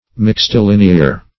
Search Result for " mixtilinear" : The Collaborative International Dictionary of English v.0.48: Mixtilineal \Mix`ti*lin"e*al\, Mixtilinear \Mix`ti*lin"e*ar\, a. [L. mixtus mixed (p. p. of miscere to mix) + E. lineal, linear.] Containing, or consisting of, lines of different kinds, as straight, curved, and the like; as, a mixtilinear angle, that is, an angle contained by a straight line and a curve.